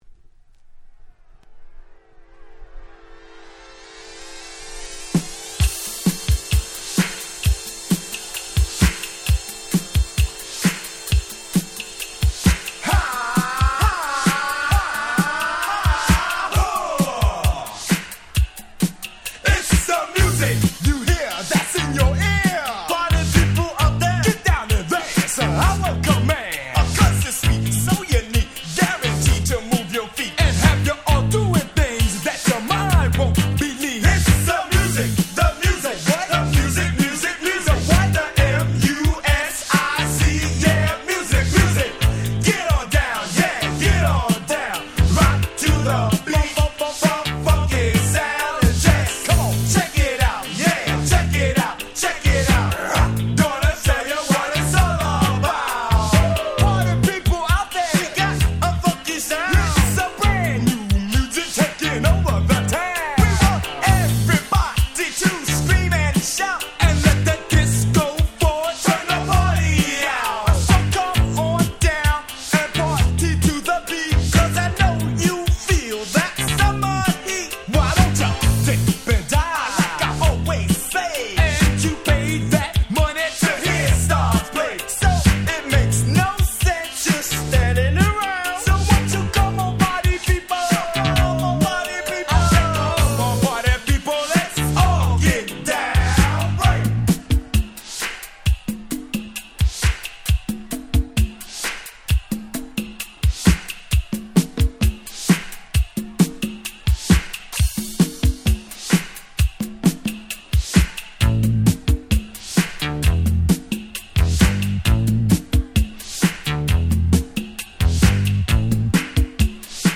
82' Old School Hip Hop Classics !!
Disco Hit !!!